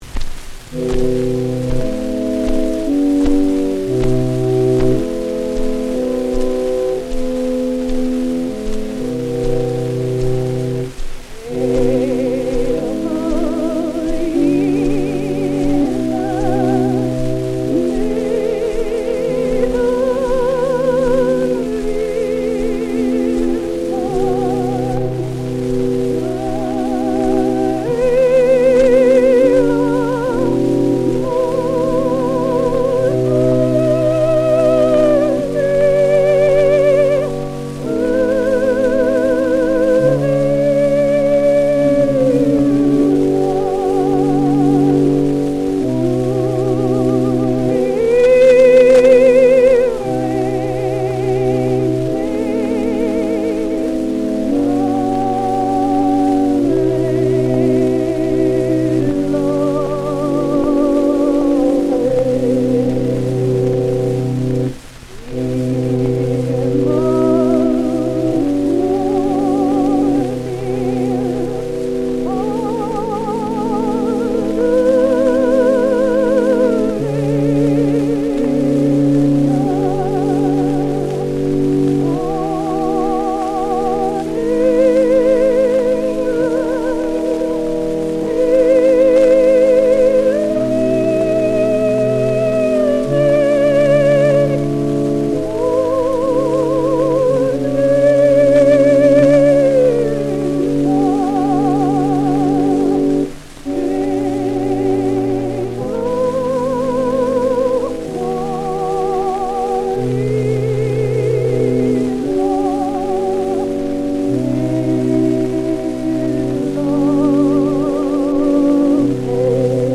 (organ)
recorded 1910-06-09
78 rpm
soprano